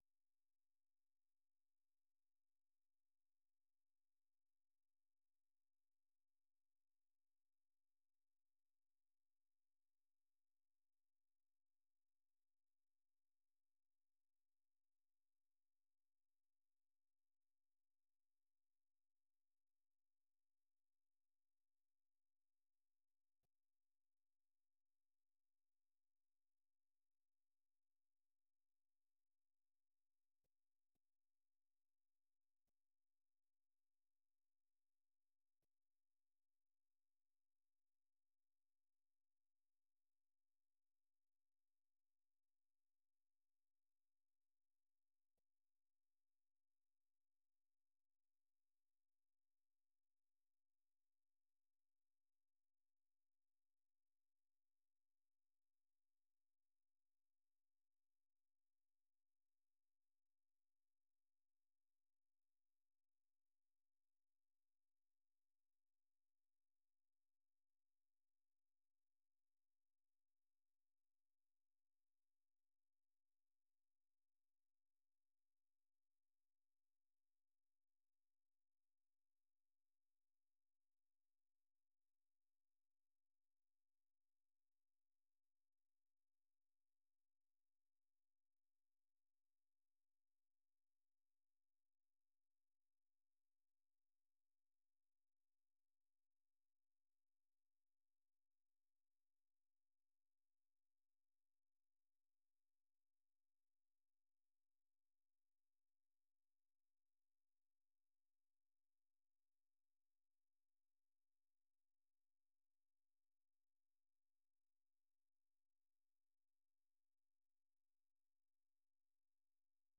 생방송 여기는 워싱턴입니다